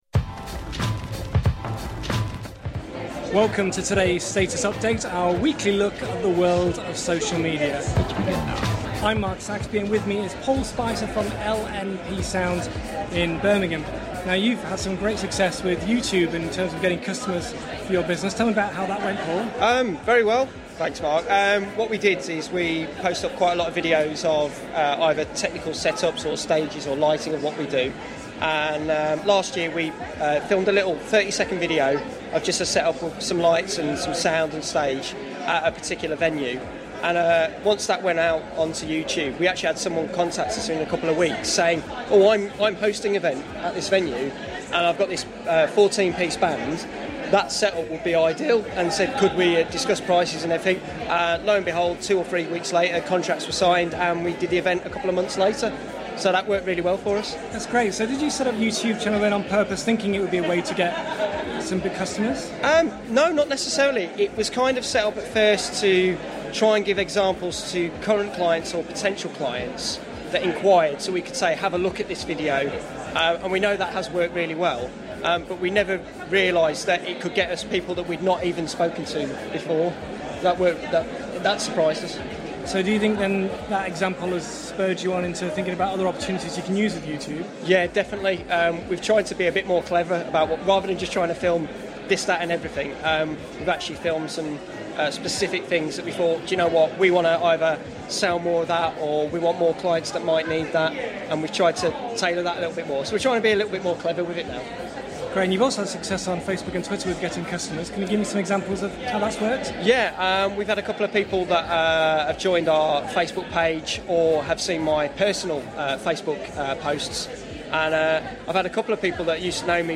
at the Business Growth Show...